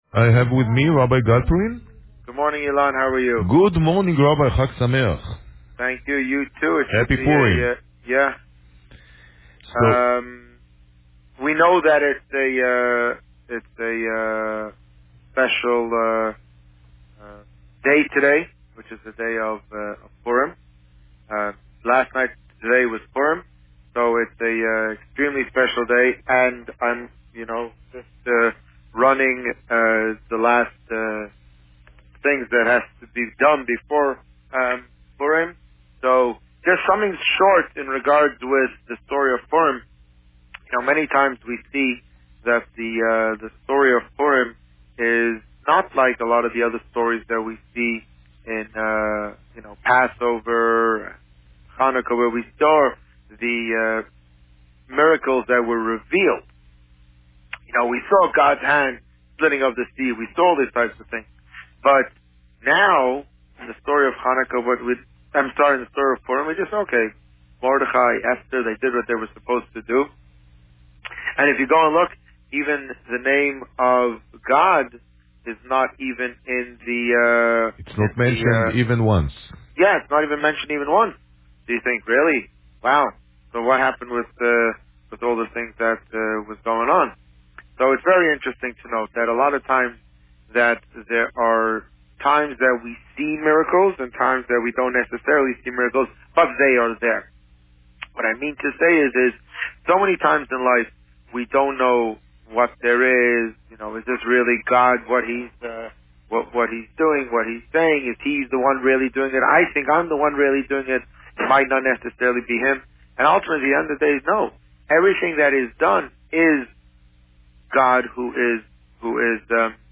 The Rabbi on Radio
The rabbi gave a shortened interview today, as he prepares for the Purim celebration later this afternoon.